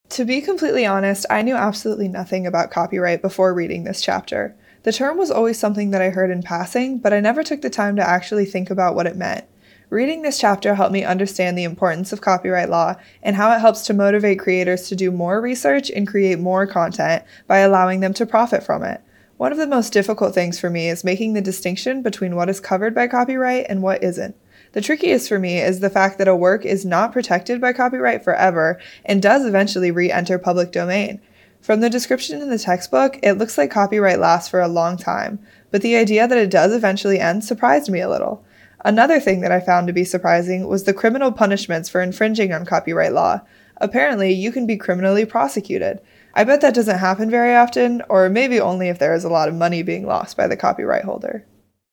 Student Voices